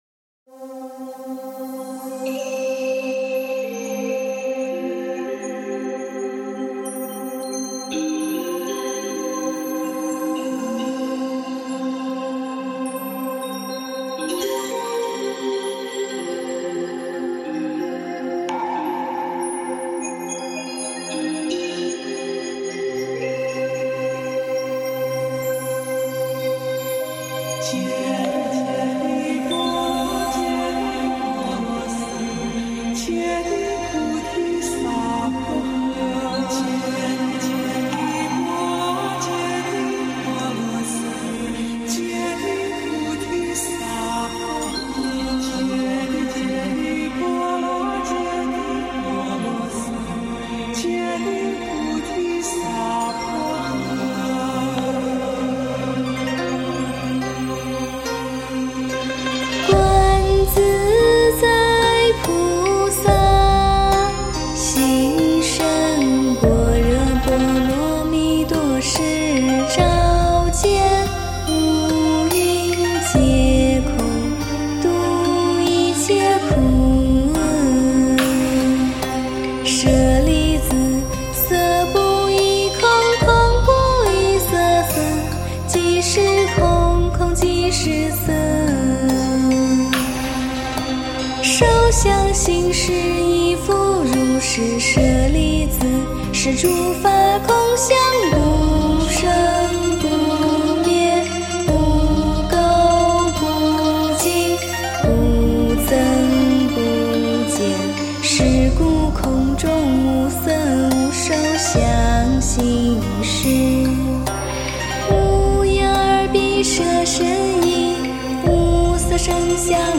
诵经
佛音 诵经 佛教音乐 返回列表 上一篇： 心经 下一篇： 大悲咒 相关文章 心经--中国佛学院法师 心经--中国佛学院法师...